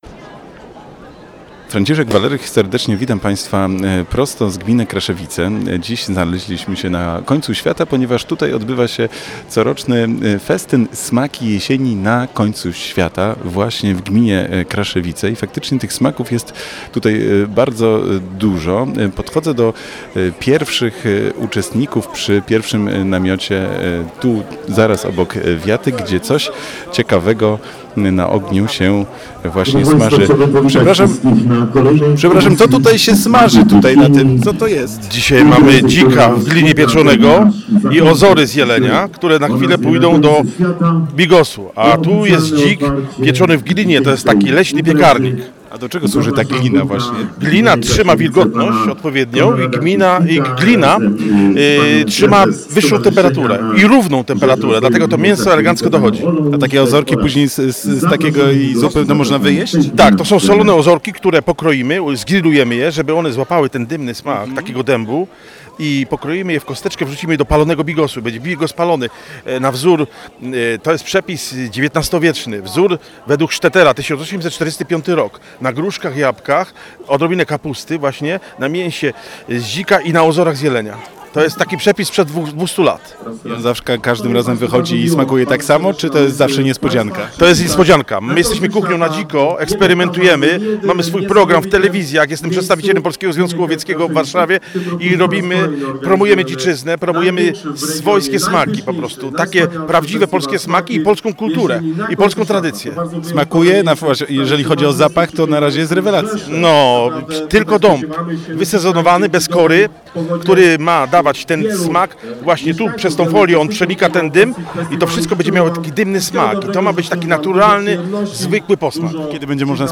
Radio Poznań miało przyjemność być częścią tego niezwykłego festiwalu, relacjonując wydarzenie na żywo.